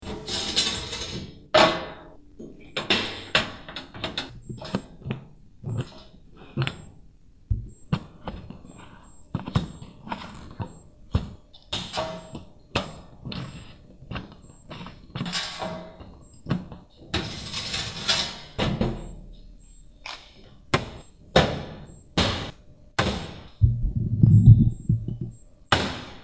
AMBIENTE DE COCINAFGL511
Tonos EFECTO DE SONIDO DE AMBIENTE de AMBIENTE DE COCINAFGL511
Ambiente_de_cocinafgl511.mp3